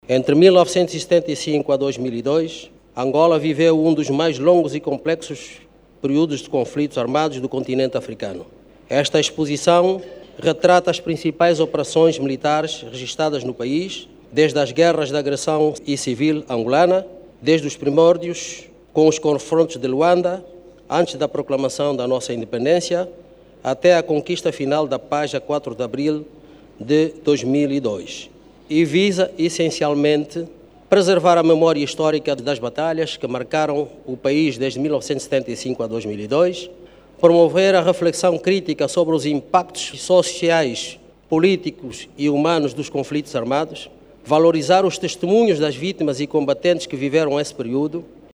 O Presidente da República e Comandante em Chefe, João Lourenço, inaugurou hoje, quinta-feira, 30, o Museu de História Militar, a exposição Caminhos de Fogo, horizonte de Paz. O Ministro de Estado e Chefe da Casa Militar do Presidente da República, Francisco Pereira Furtado, diz que a exposição retrata as principais operações militares registadas em Angola, entre os anos, 1975 a 2002, altura da conquista da Paz.
MINISTRO-FURTADO-1-12-HRS.mp3